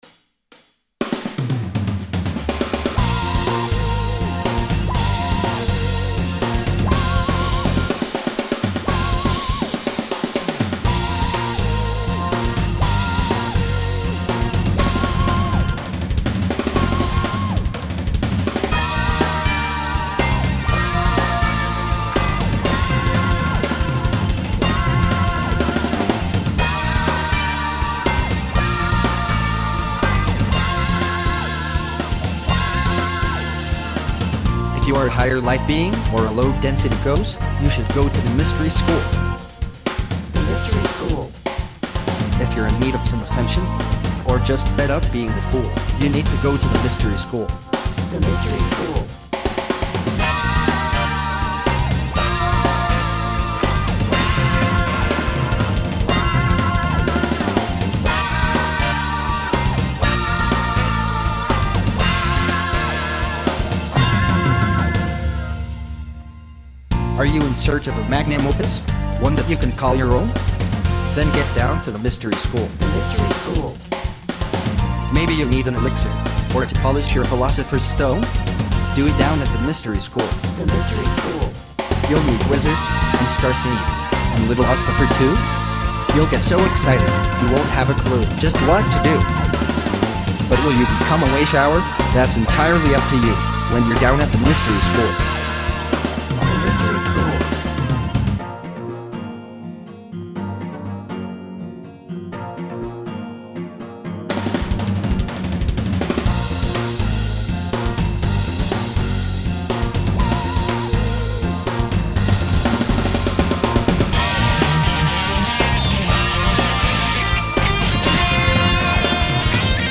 oompah and belly dance